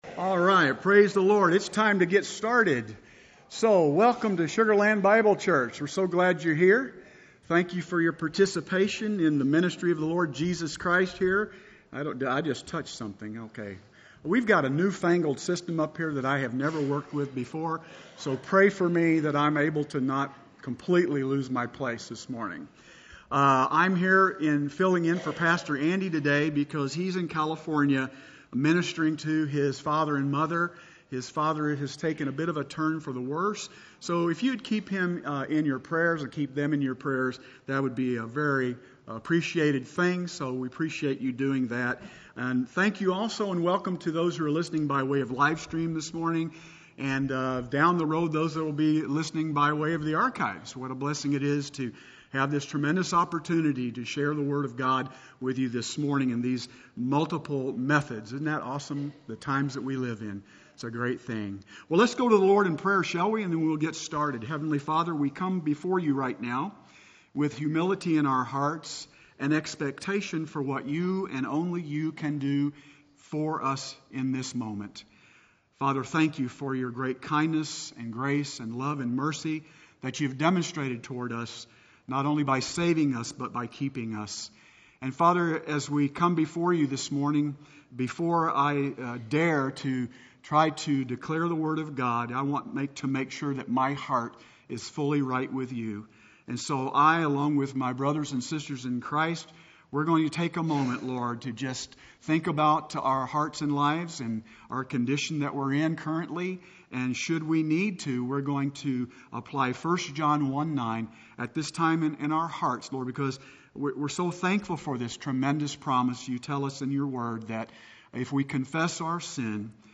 Home / Sermons / Law & Grace 043: Relationship, Position, and Blessing – Does It Matter?